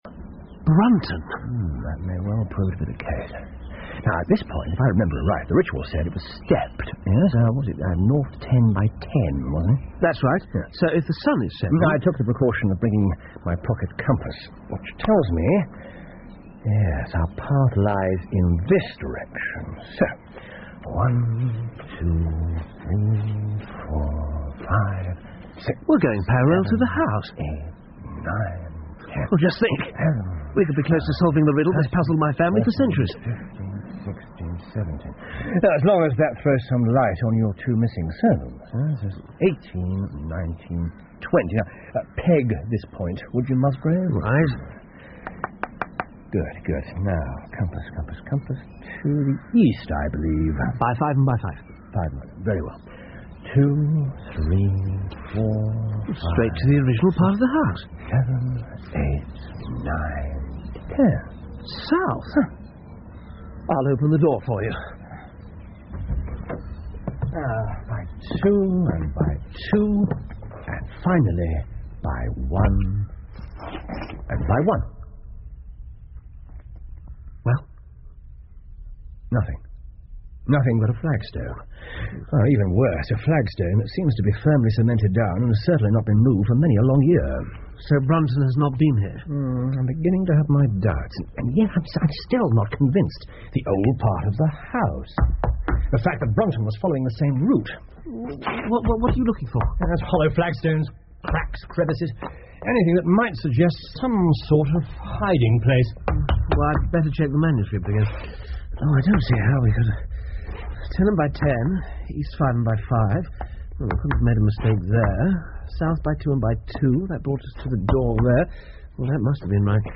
福尔摩斯广播剧 The Musgrave Ritual 6 听力文件下载—在线英语听力室